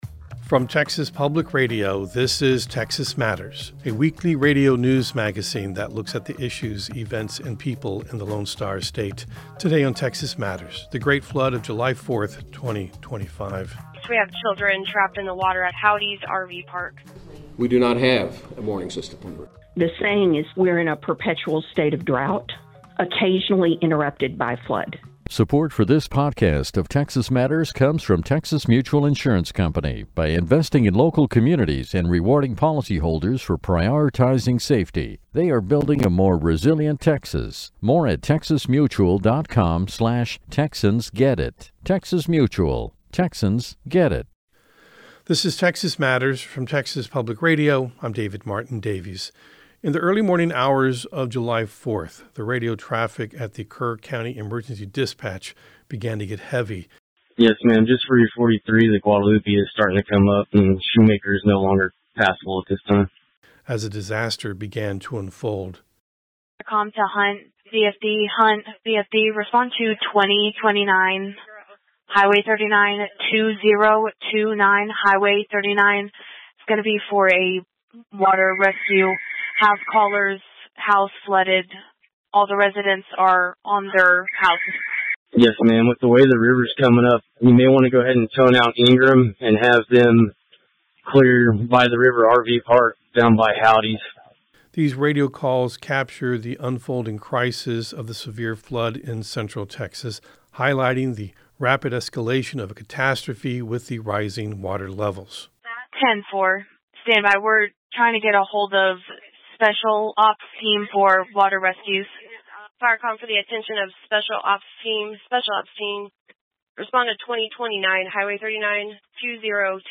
Texas Matters is a statewide news program that spends half an hour each week looking at the issues and culture of Texas.